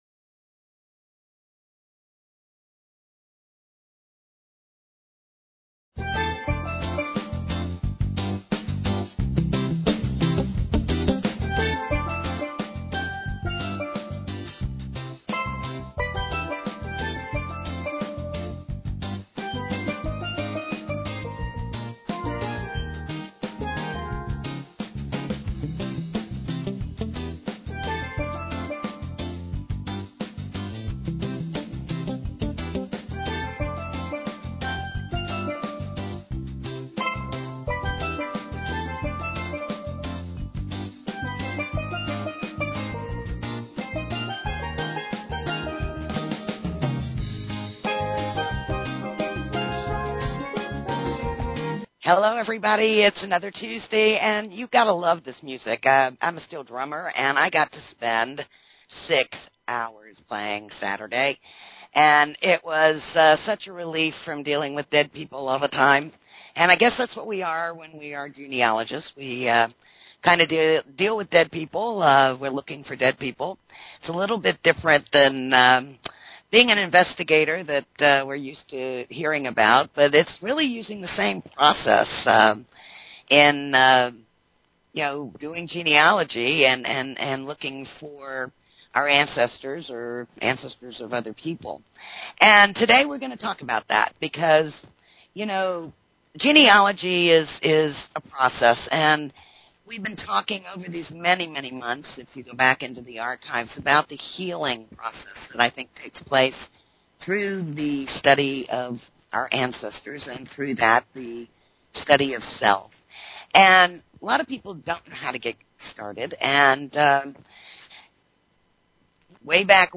Talk Show Episode, Audio Podcast, Where_Genealogy_and_Spirit_Connect and Courtesy of BBS Radio on , show guests , about , categorized as